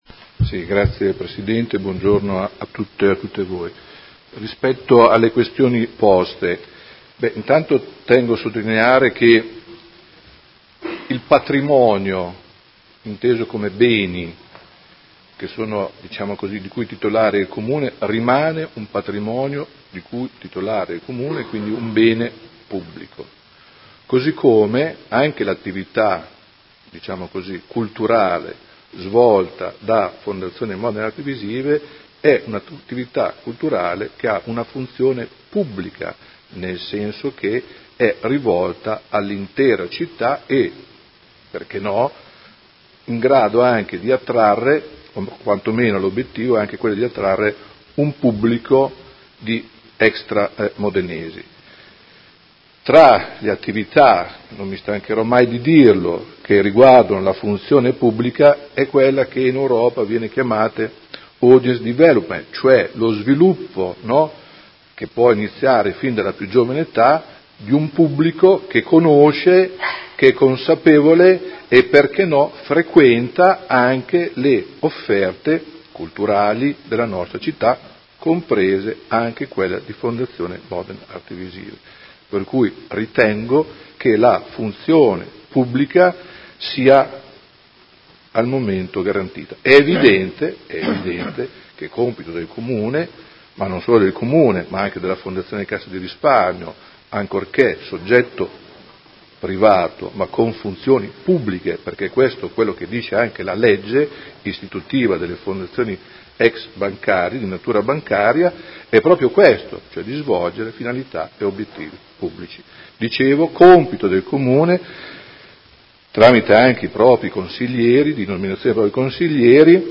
Seduta del 10/01/2019 Interrogazione del Gruppo Consiliare Movimento cinque Stelle avente per oggetto: Situazione FMAV